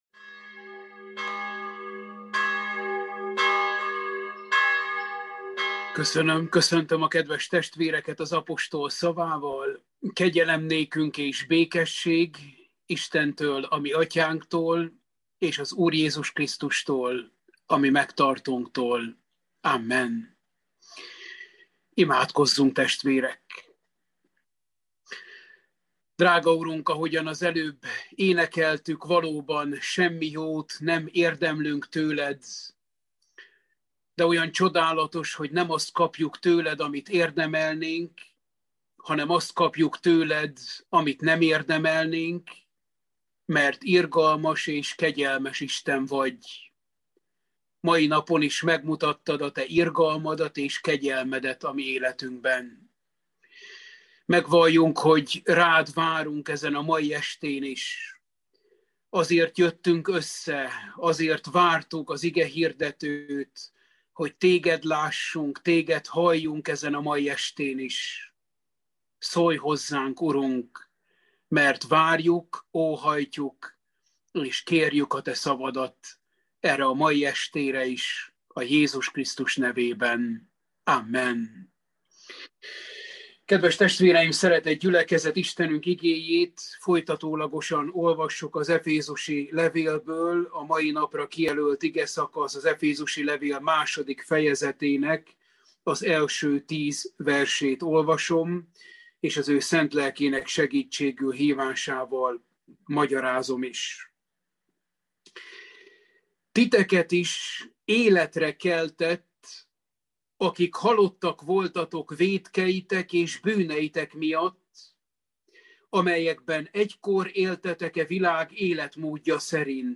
Efézusi levél – Bibliaóra 4